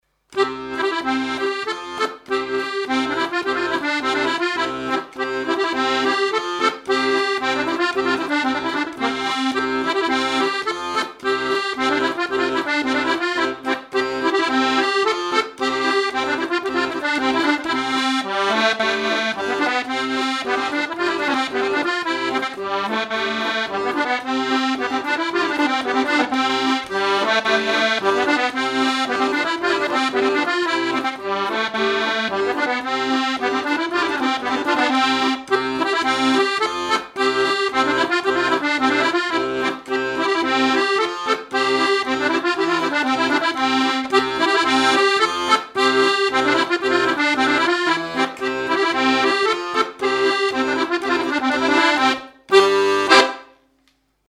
Mémoires et Patrimoines vivants - RaddO est une base de données d'archives iconographiques et sonores.
danse : mazurka
Pièce musicale inédite